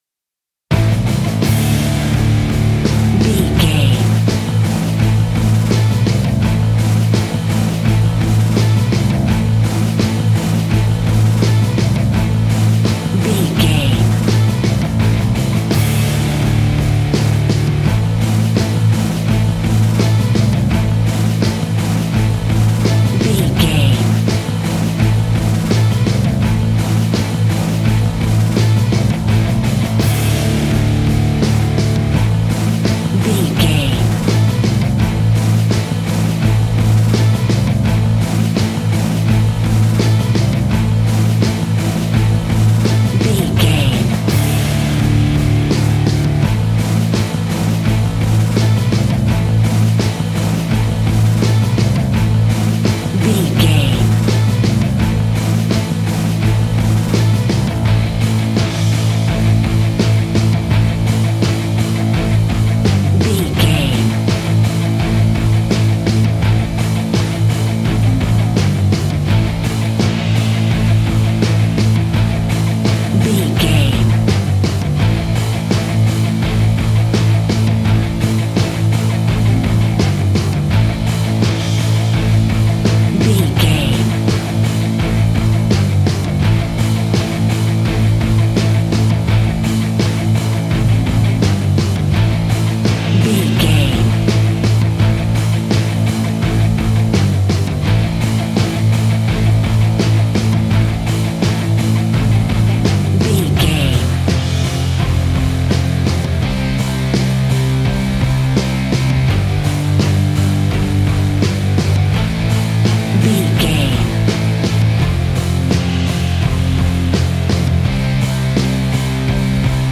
Epic / Action
Aeolian/Minor
Slow
hard rock
heavy metal
rock instrumentals
Heavy Metal Guitars
Metal Drums
Heavy Bass Guitars